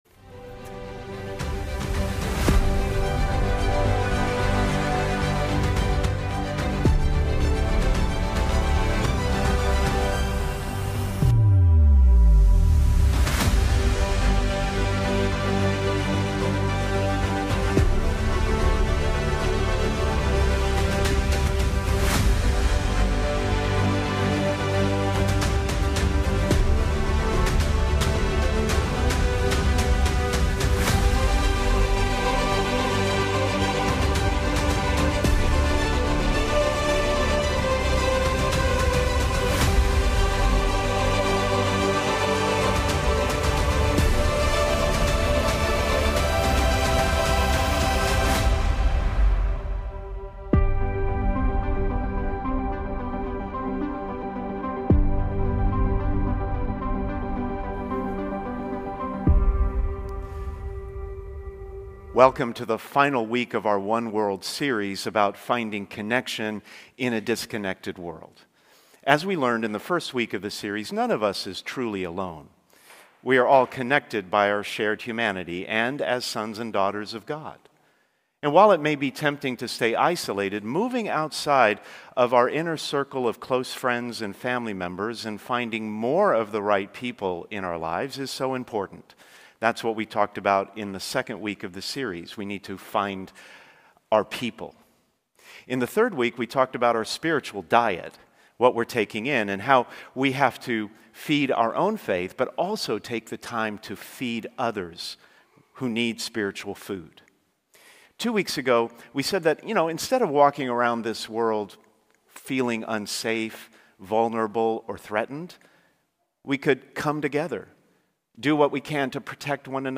Sermons | St. Hilary Church